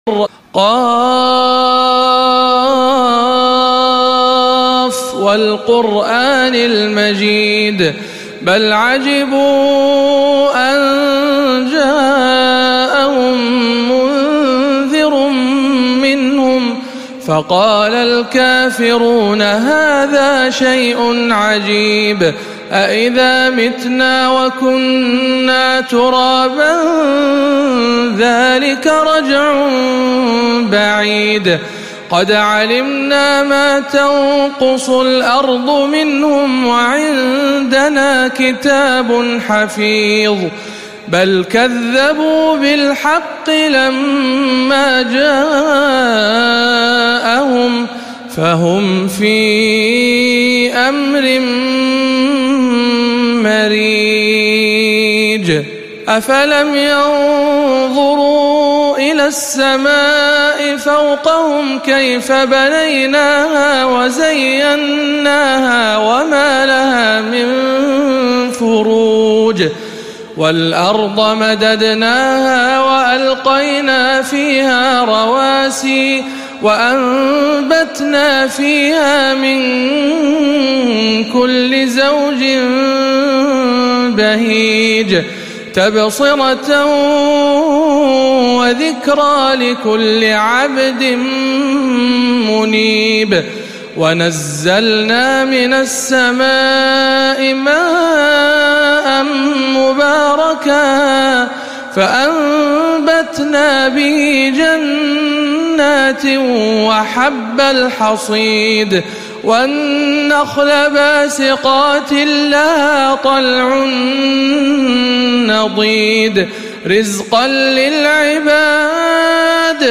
سورة ق بمسجد الحسين بن علي بخليص - رمضان 1437 هـ